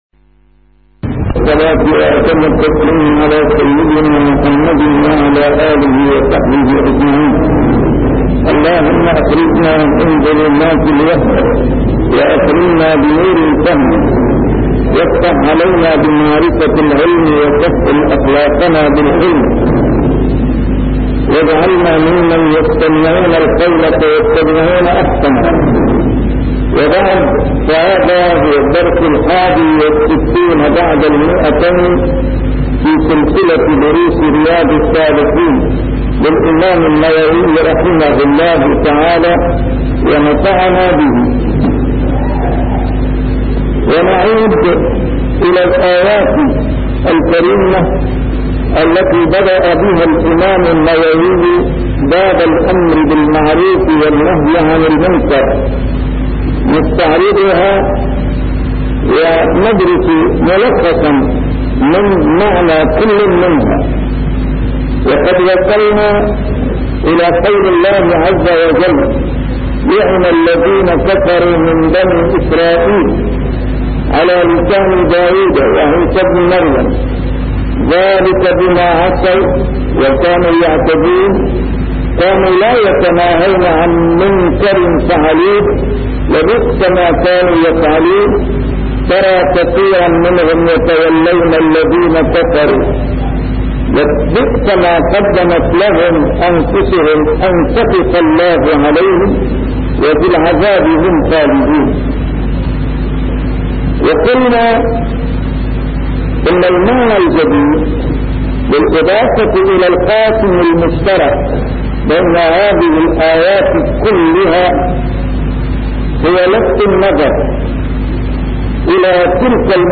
A MARTYR SCHOLAR: IMAM MUHAMMAD SAEED RAMADAN AL-BOUTI - الدروس العلمية - شرح كتاب رياض الصالحين - 261- شرح رياض الصالحين: الأمر بالمعروف